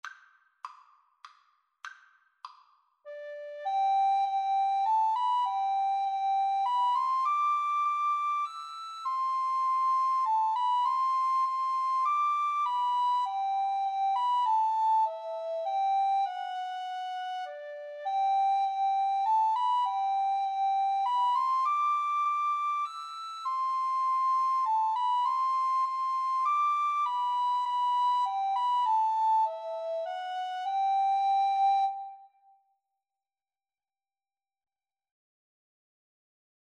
Soprano RecorderAlto Recorder
3/4 (View more 3/4 Music)
Christmas (View more Christmas Recorder Duet Music)